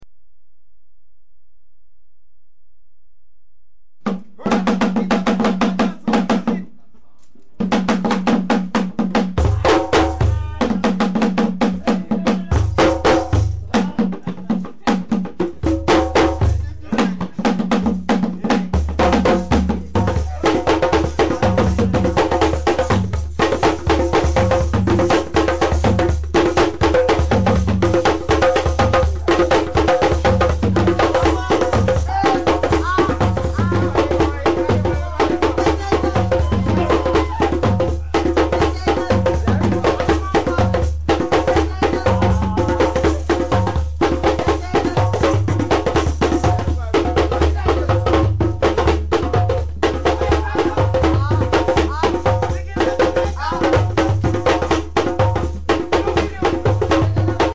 The sabar is traditional drum from the West African nation of Senegal. It is generally played with one hand and one stick.
sabar.mp3